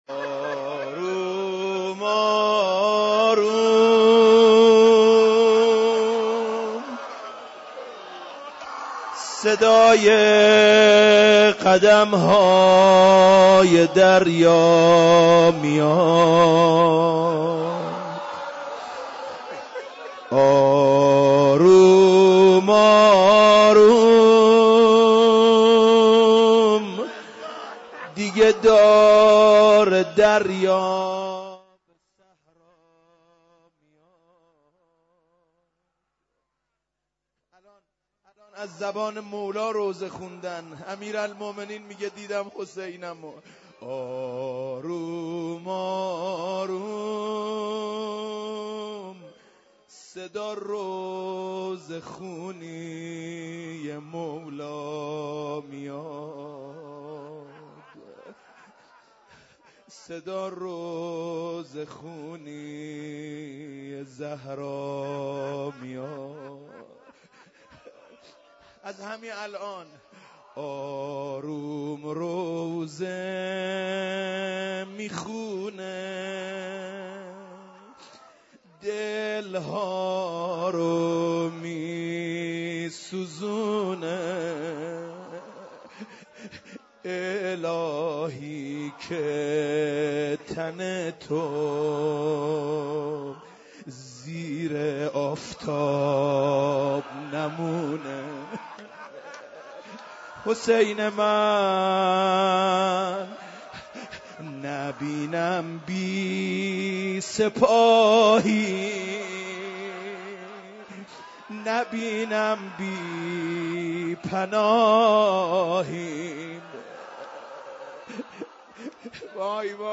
مسجد هدایت - روضه